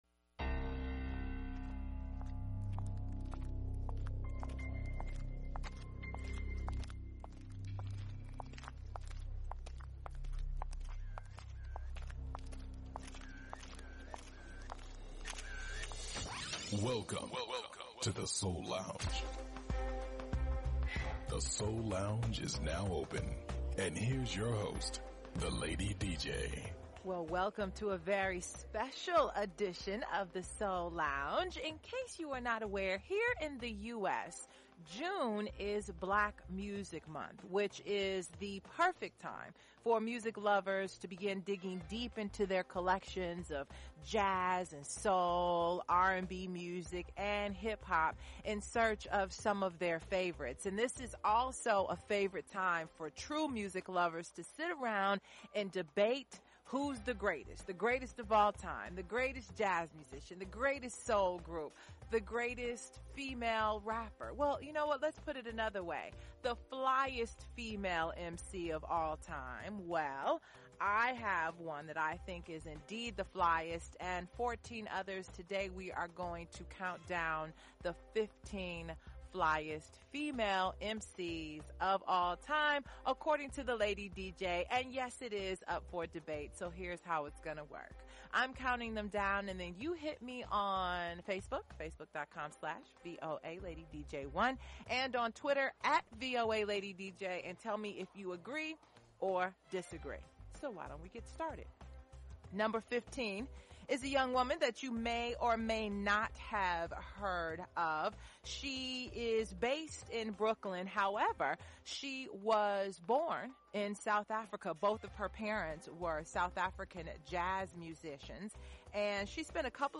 music, interviews and performances
Neo-Soul
conscious Hip-Hop
Classic Soul